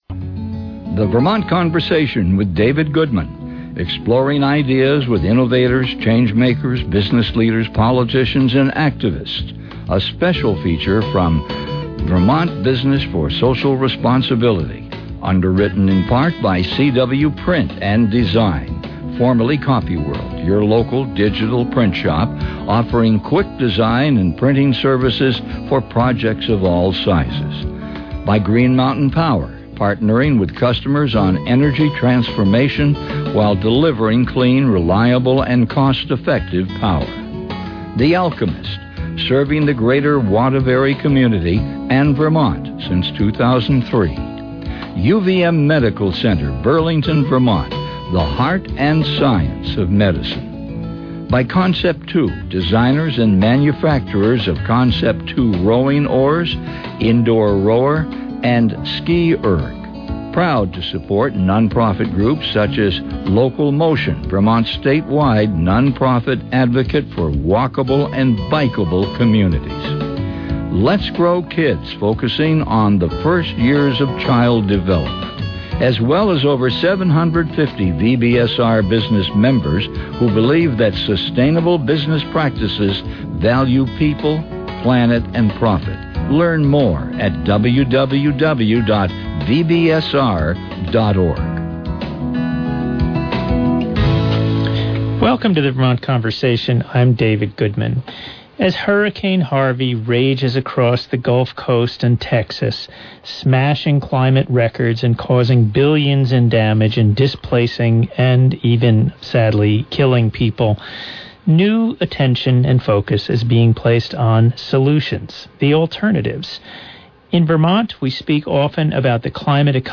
This Vermont Conversation was recorded live at Bridgeside Books in Waterbury, VT.